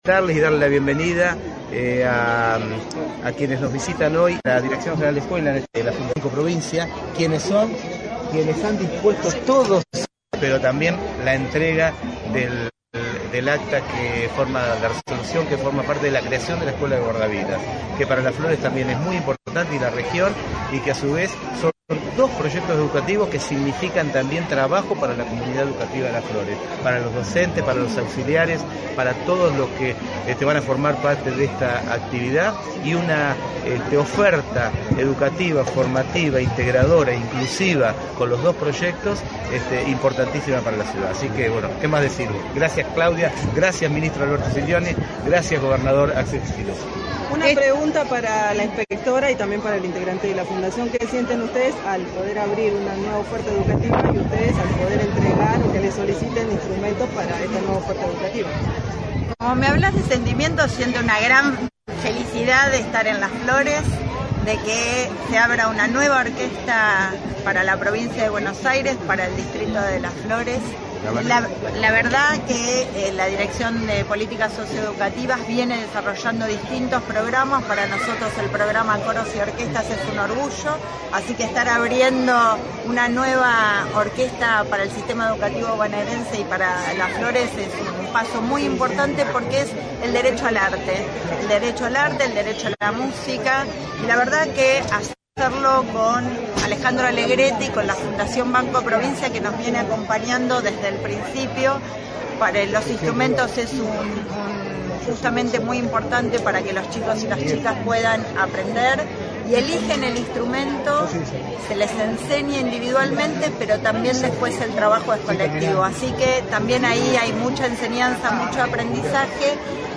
Conferencia-Orquesta.mp3